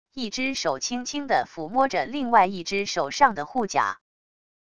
一只手轻轻的抚摸着另外一只手上的护甲wav音频